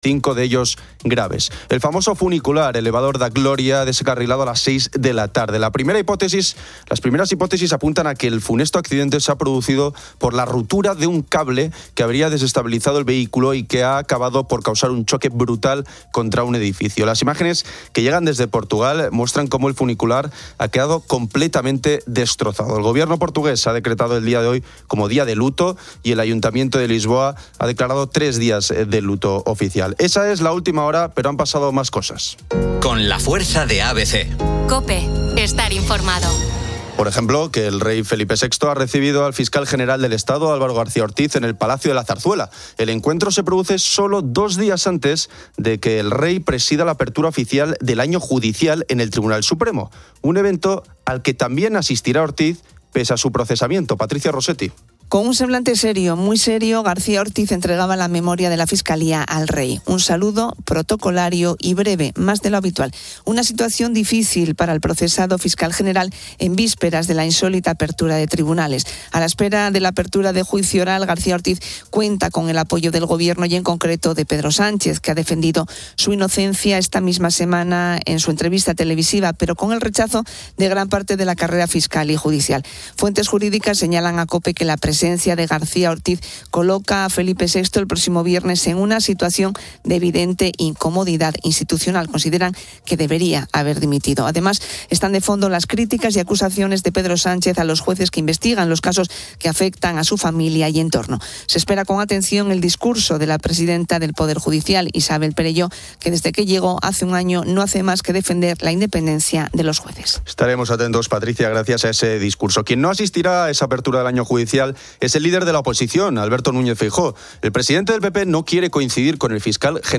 El programa también ofreció pronósticos del tiempo y llamadas de oyentes.